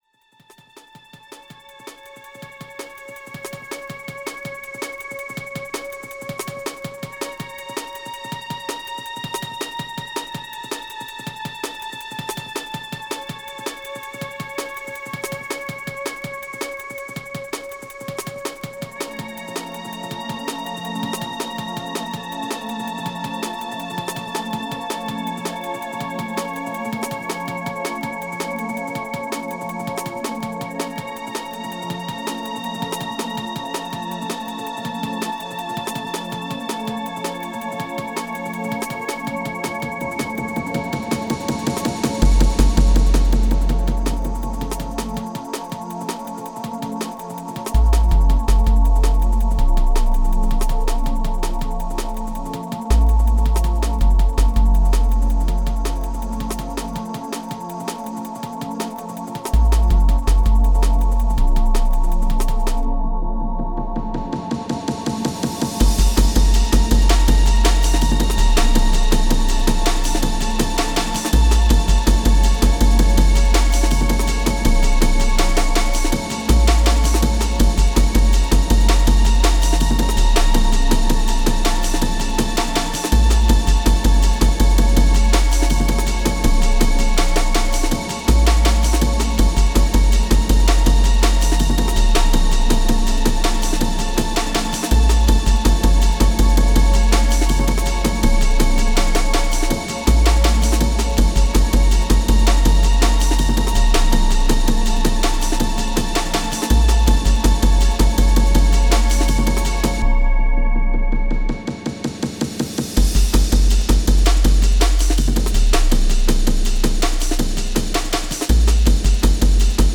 professionally remastered